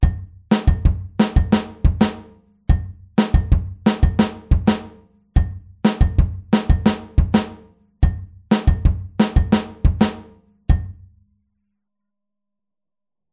Rechte Hand auf Kopfbecken (bell/head) oder Kuhglocke (cowbell)
Bei der 4tel-Variante spielt die rechte Hand nicht mehr auf dem HiHat sondern wegen des Punches auf dem Kopfbecken (bell/head aber nicht mit der Kuppe) oder der Kuhglocke (cowbell).
Groove17-4tel.mp3